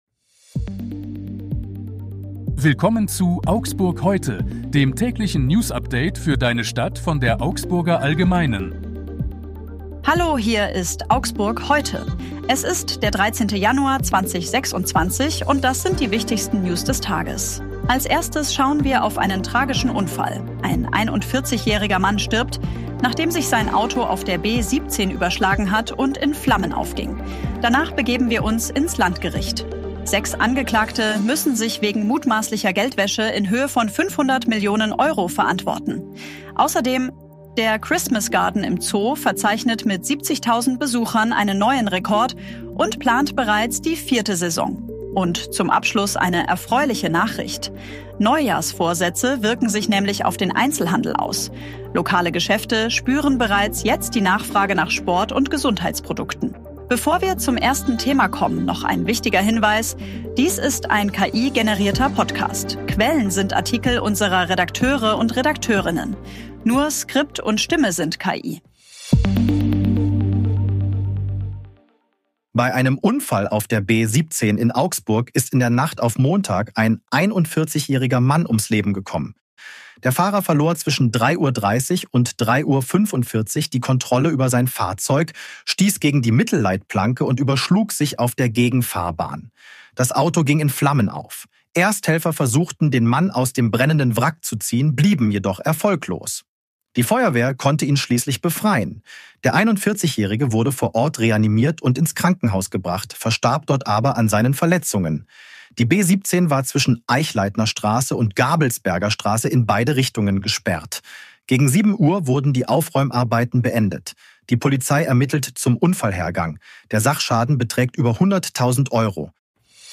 Die aktuellen Nachrichten aus Augsburg vom 13. Januar 2026.
Stimme sind KI.